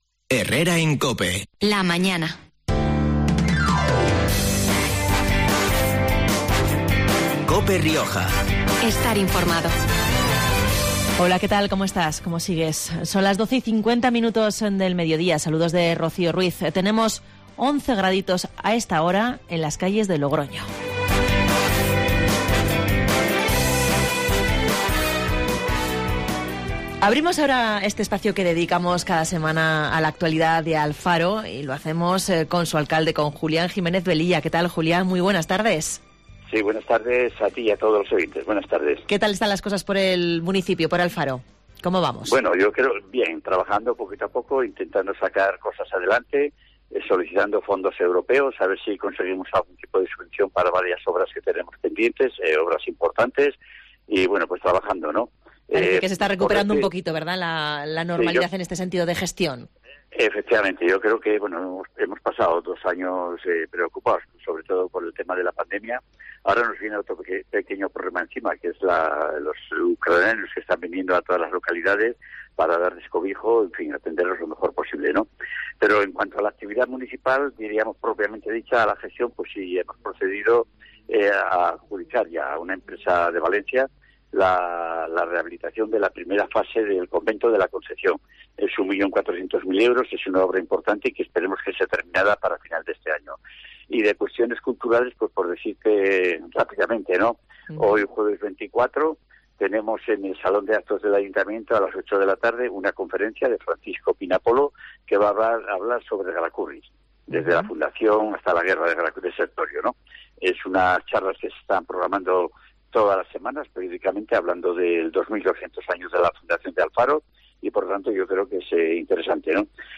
El alcalde de Alfaro en los micrófonos de COPE Rioja